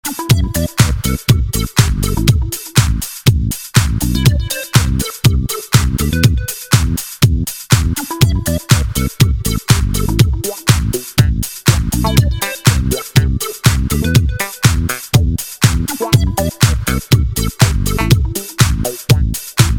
• Качество: 128, Stereo
Electronic
без слов
nu disco
Бодрый рингтон